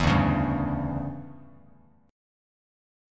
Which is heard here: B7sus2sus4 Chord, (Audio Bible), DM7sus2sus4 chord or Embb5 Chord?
DM7sus2sus4 chord